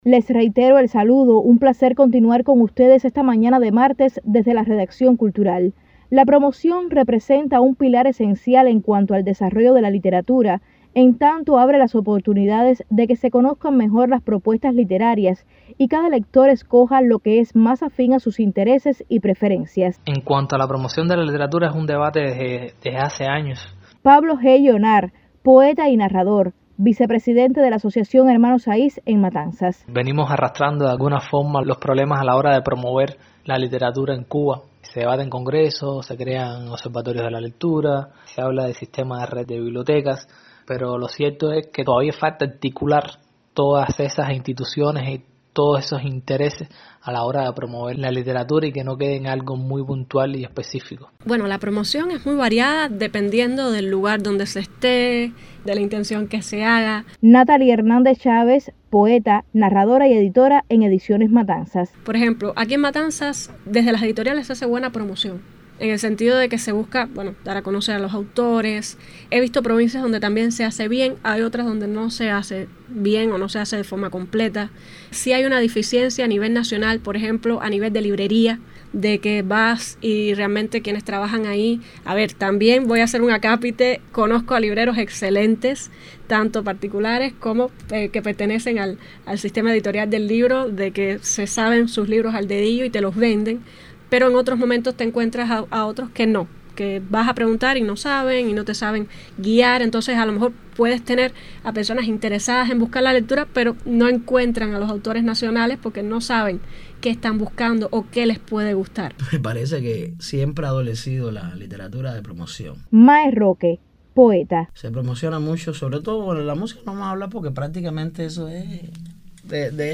Estas son las opiniones de algunos escritores matanceros.